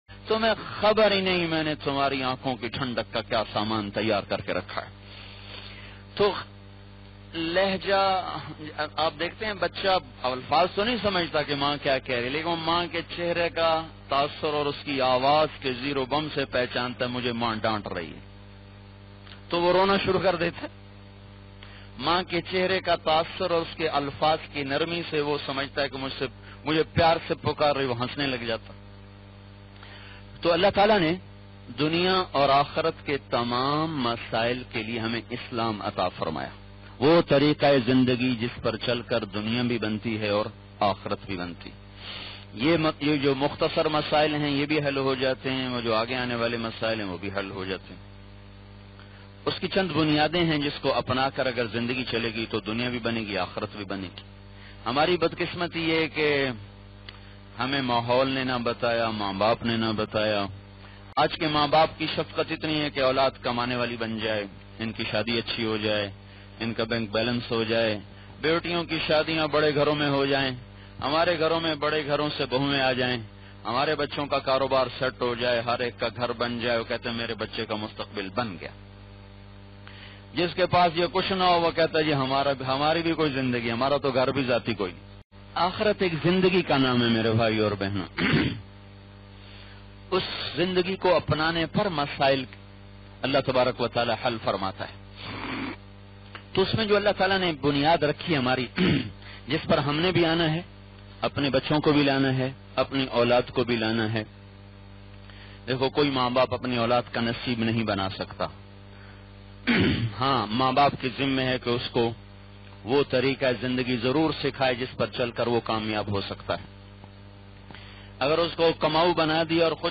Aulad Ki Tarbiyat Kaisay Karen, listen or play online mp3 urdu bayan by Maulana Tariq Jameel.